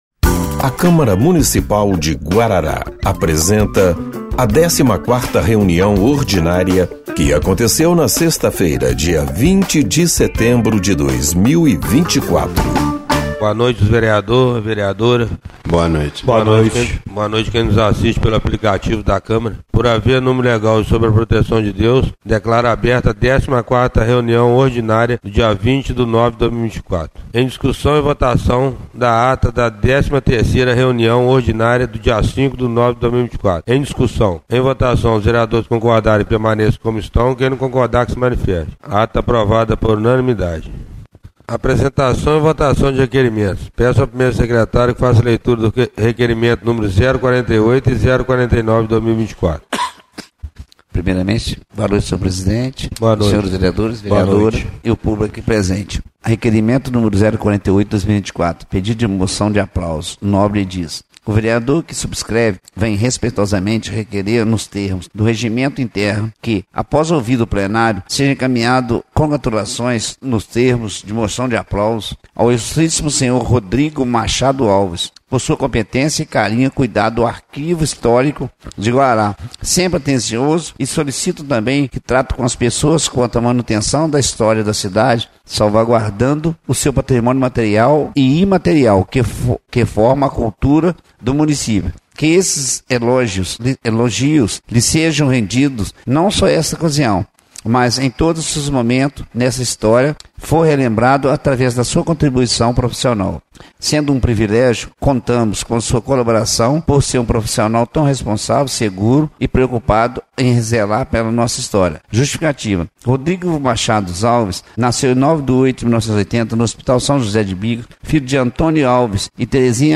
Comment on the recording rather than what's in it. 14ª Reunião Ordinária de 20/09/2024